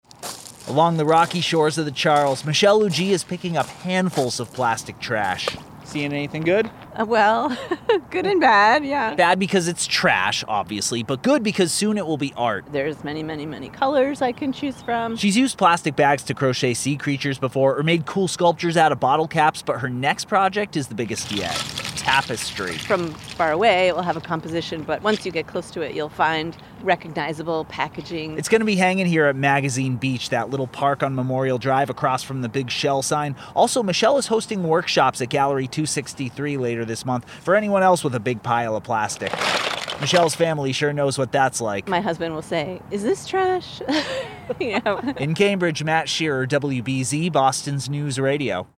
Interview with WBZ Radio, Magazine Beach Tapestry, May 9, 2022